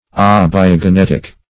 Search Result for " abiogenetic" : Wordnet 3.0 ADJECTIVE (1) 1. originating by abiogenesis ; The Collaborative International Dictionary of English v.0.48: Abiogenetic \Ab`i*o*ge*net"ic\, a. (Biol.)
abiogenetic.mp3